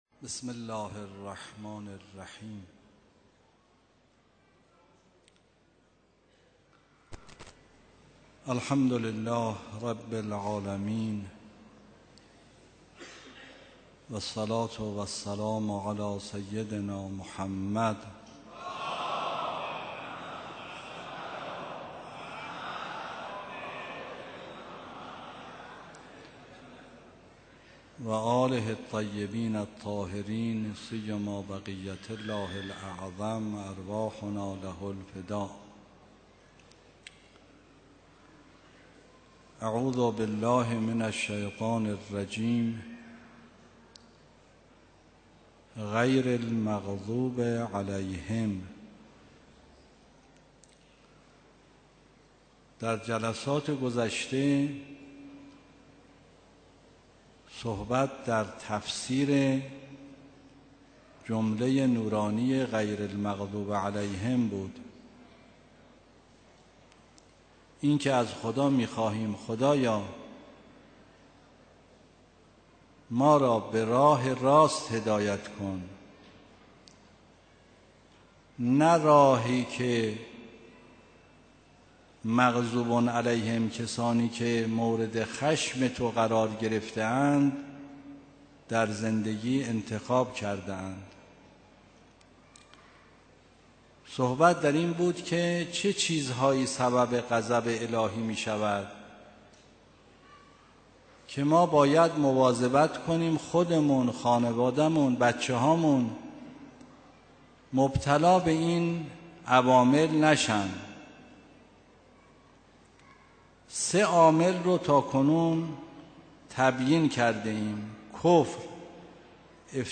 گروه اندیشه: آیت‌الله ری‌شهری در جلسه تفسیر خود با بیان اینکه اسلام دین آسانی است، به اسباب غضب الهی اشاره کرد و گفت: کفر، افترای به خدا، کشتن پیامبران و اسلام‌ستیزی چهار عامل غضب الهی هستند.